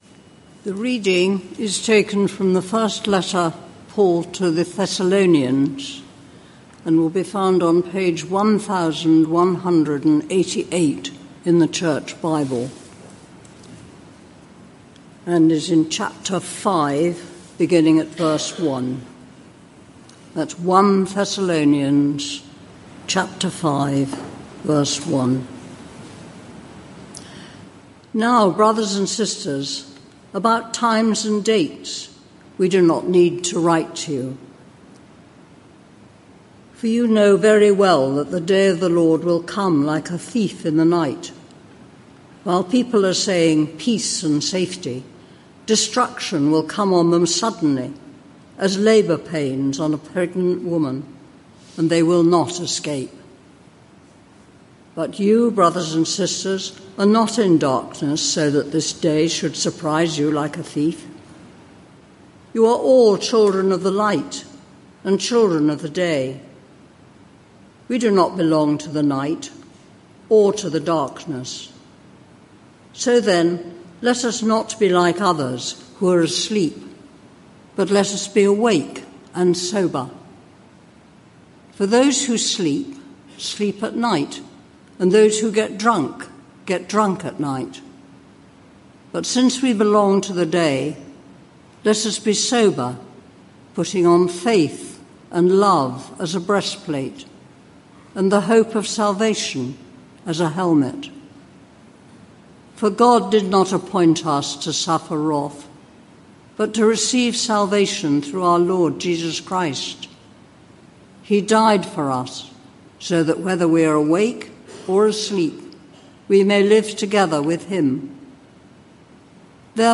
This sermon is part of a series